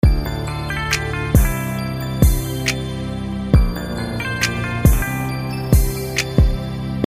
Снимите пожалуйста кто-нибудь партию гитары отсюда, или подскажите какие ноты здесь